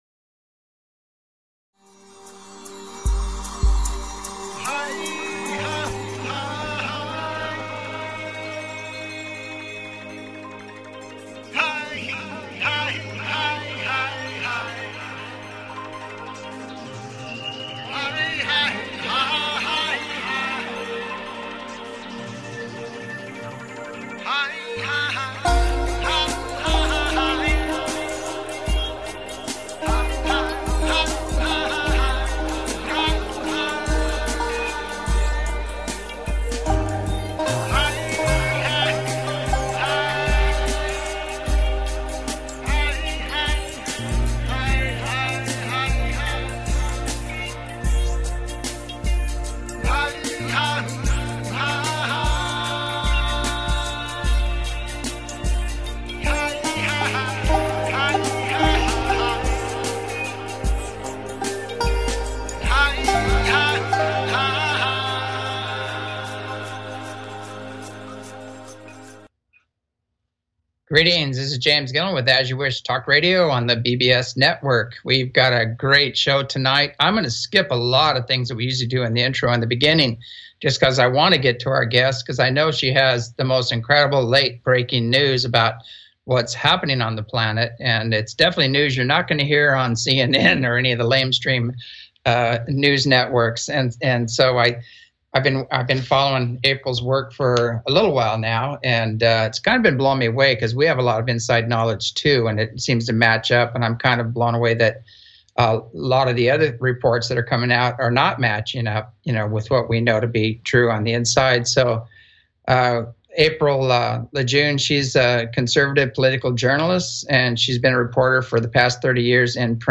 As You Wish Talk Radio